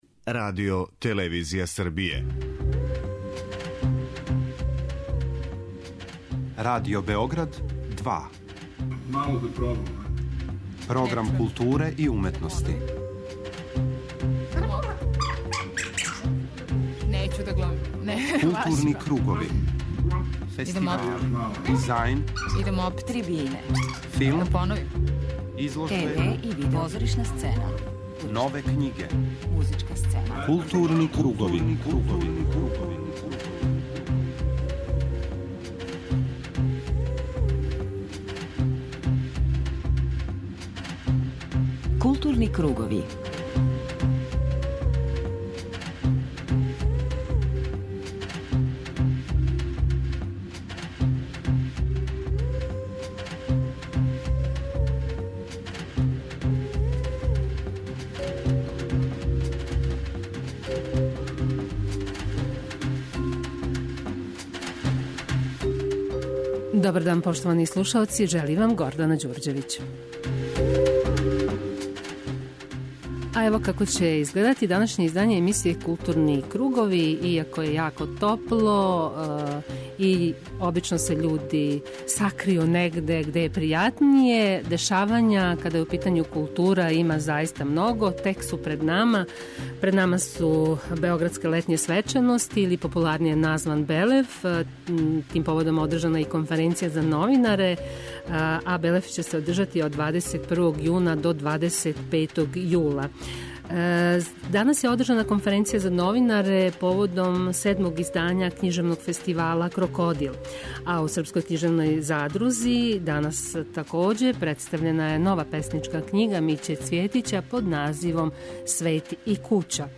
Јубиларно 60. Стеријино позорје ове године траје од 26. маја до 3. јуна. У данашњим Маскама чућете како је протекло завршно вече фестивала, као и разговор са награђеним ауторима.